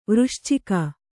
♪ vřścika